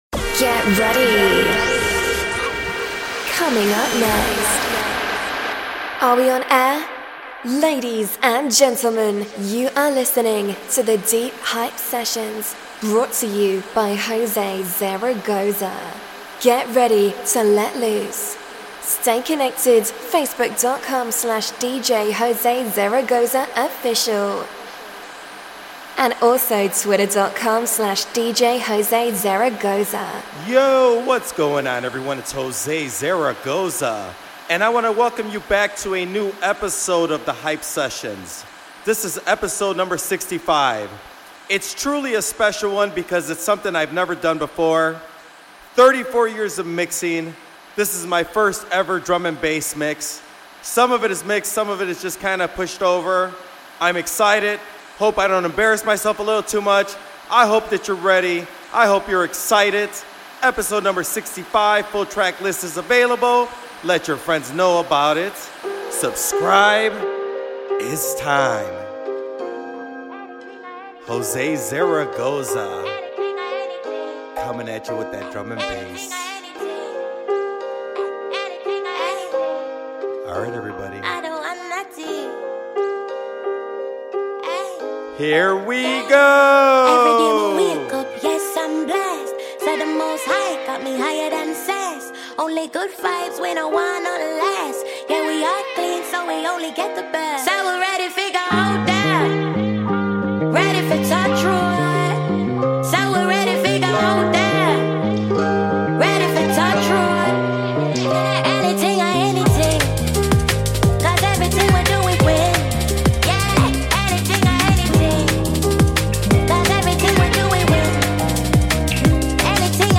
drum and bass show